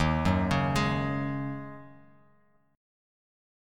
Listen to D#m11 strummed